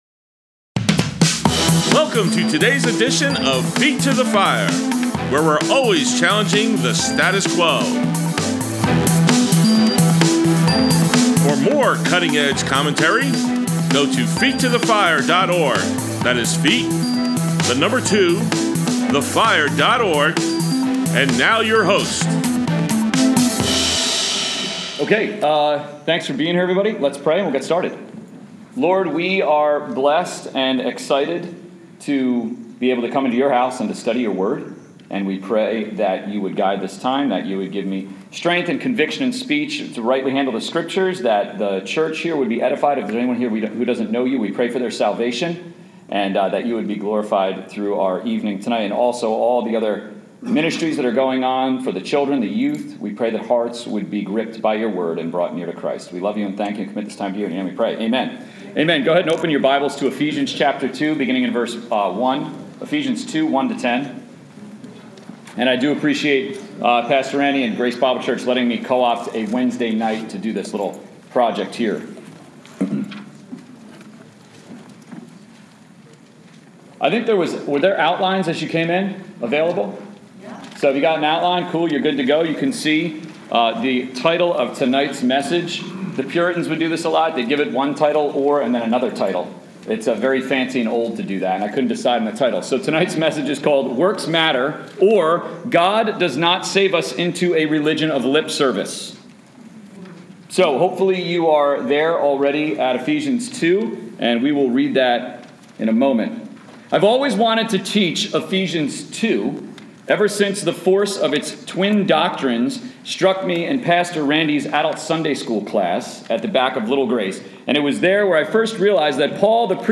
Wednesday Night Prayer 7/14/21 Works Matter or God Does Not Save Us Into a Religion of Lip Service Ephesians 2:1-10